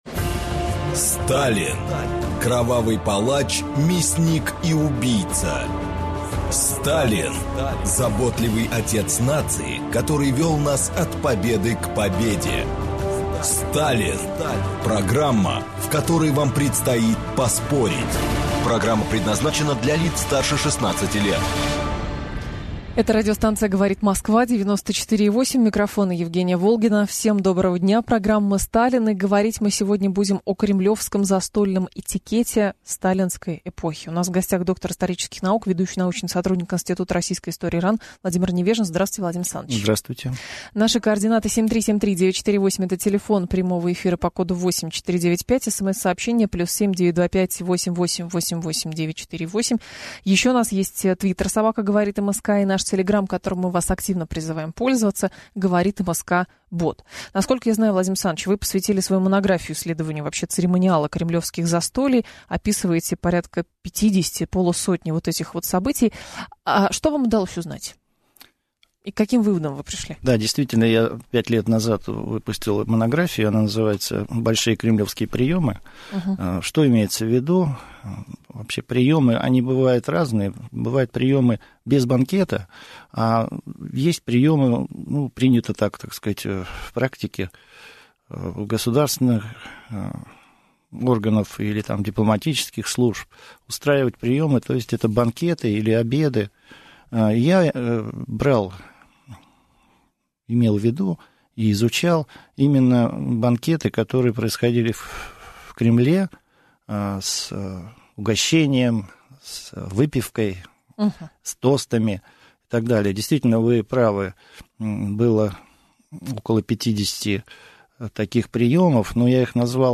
Аудиокнига Застолья сталинской элиты | Библиотека аудиокниг